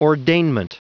Prononciation du mot ordainment en anglais (fichier audio)
Prononciation du mot : ordainment